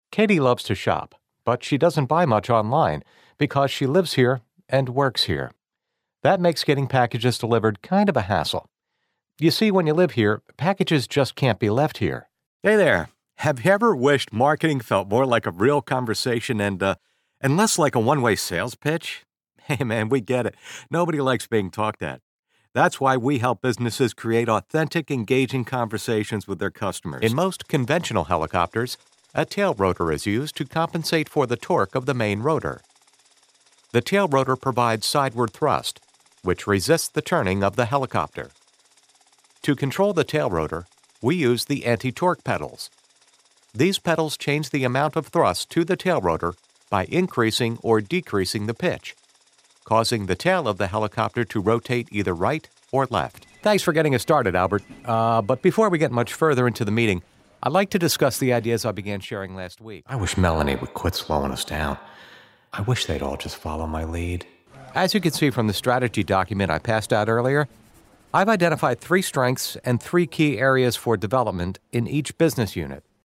Explainer & Whiteboard Video Voice Overs
Adult (30-50) | Older Sound (50+)